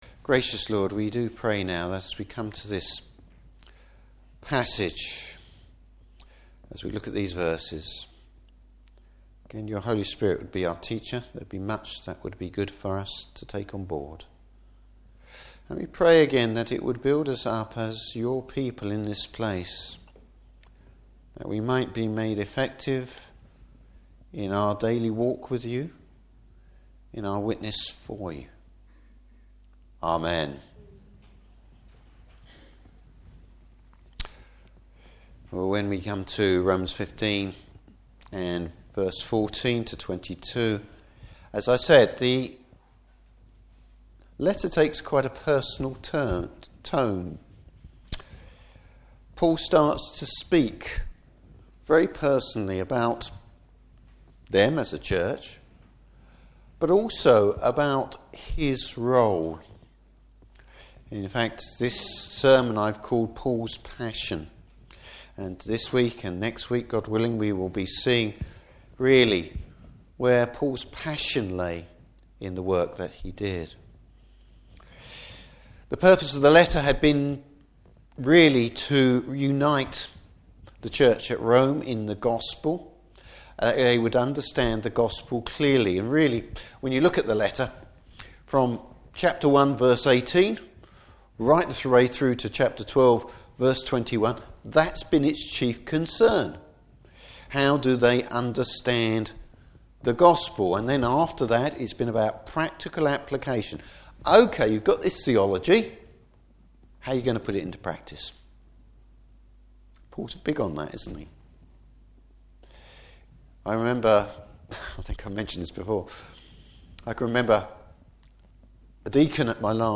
Service Type: Morning Service Paul’s ministry to the Gentiles.